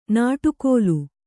♪ nāṭu kōlu